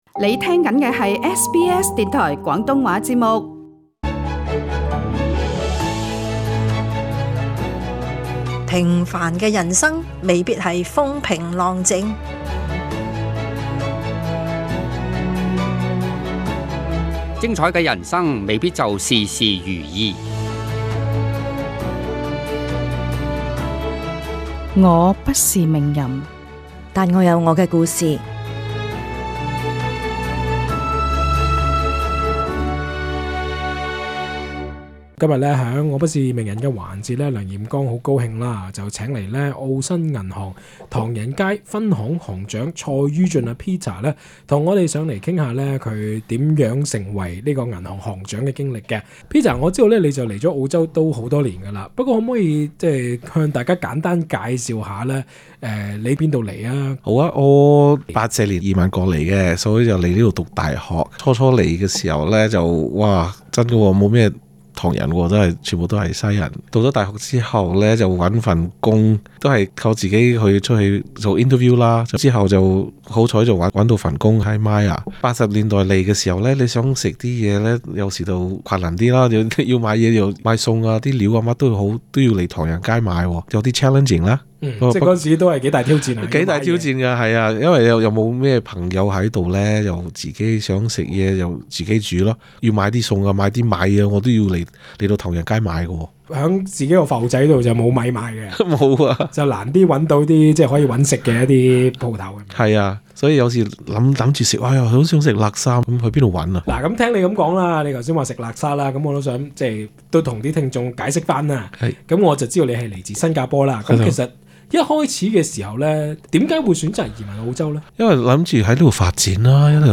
Source: SBS Cantonese